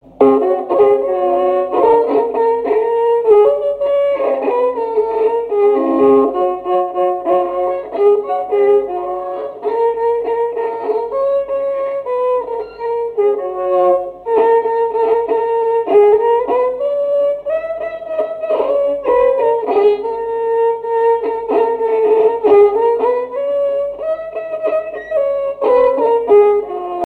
Mothe-Achard (La)
cantique
répertoire d'un violoneux
Pièce musicale inédite